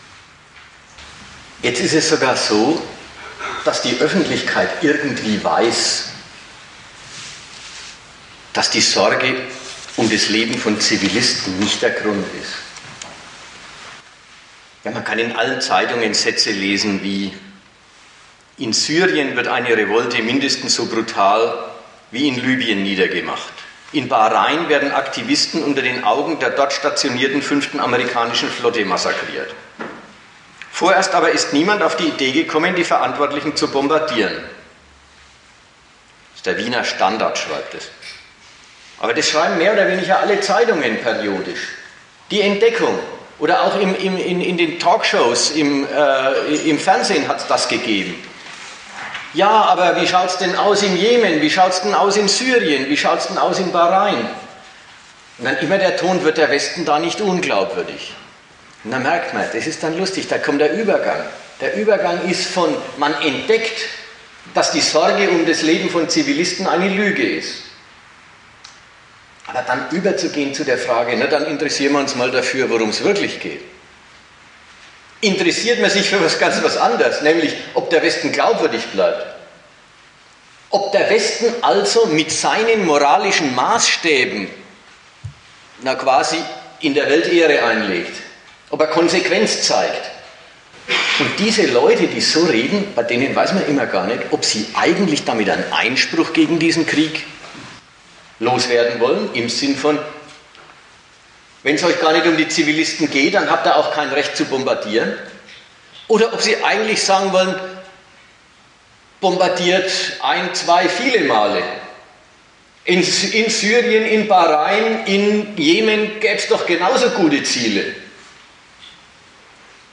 Dozent
Gastreferenten der Zeitschrift GegenStandpunkt